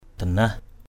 /d̪a-nah/ (đg.) gắt gỏng. danah hatai dqH h=t bực mình.